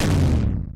fireball_explode.mp3